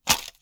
Close Combat Break Bone 5.wav